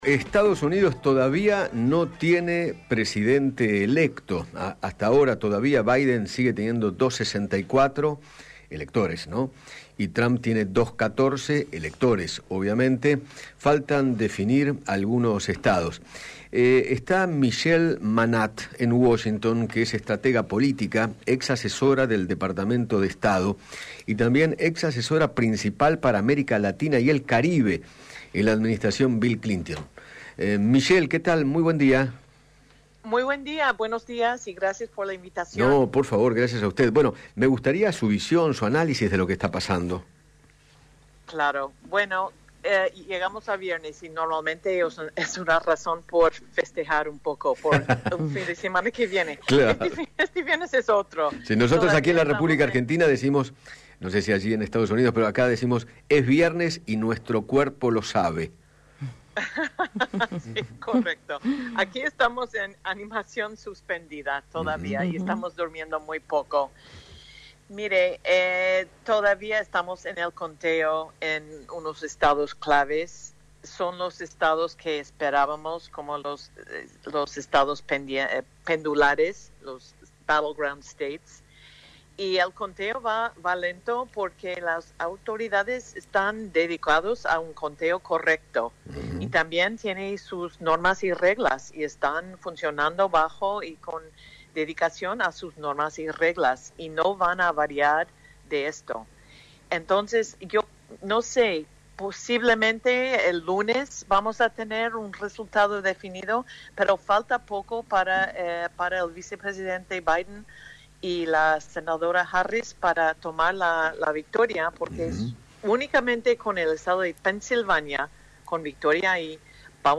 Michele Manatt, ex-asesora principal para America Latina y el Caribe en la Administracion de Bill Clinton, dialogó con Eduardo Feinmann sobre la definición de las elecciones presidenciales de Estados Unidos y manifestó que si gana Joe Biden, la preocupación central será “encontrar una solución a la crisis de la pandemia”.